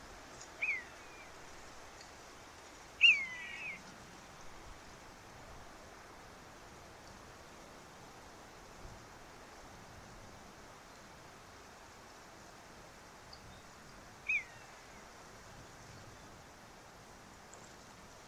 Miñato común
Buteo buteo
Canto